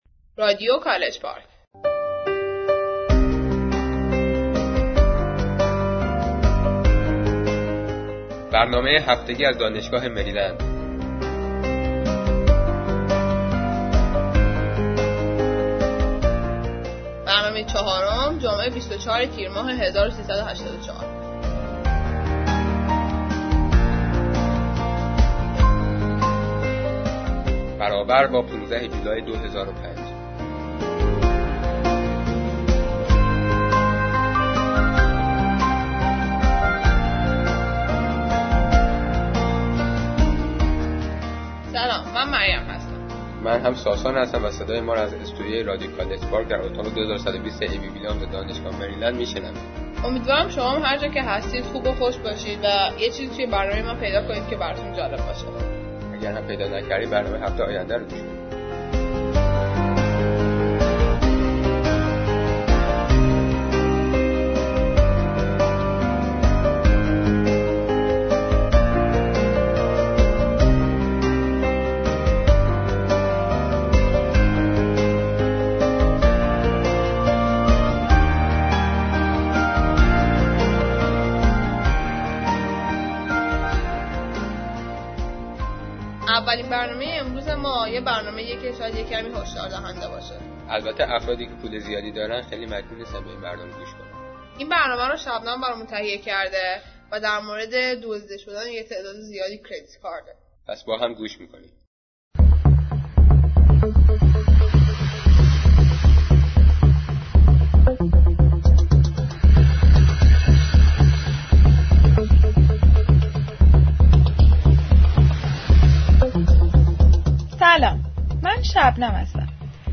Childhood Memories (Short Story)
Science News